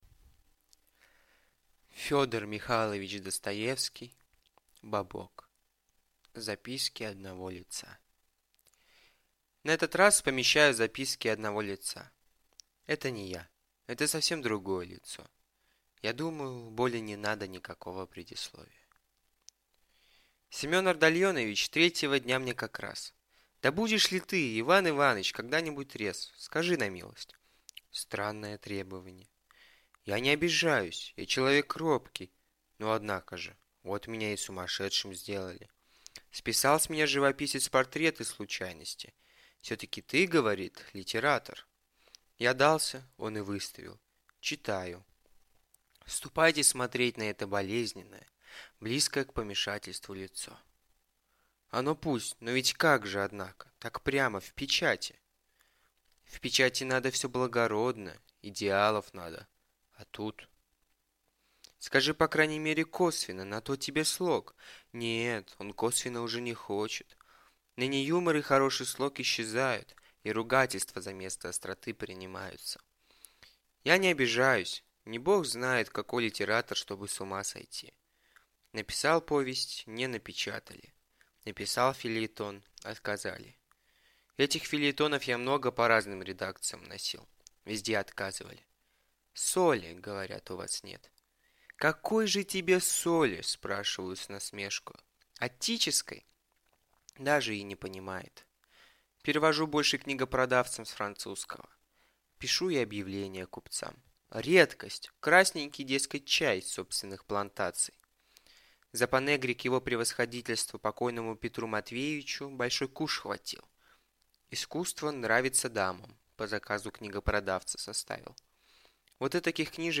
Аудиокнига Бобок | Библиотека аудиокниг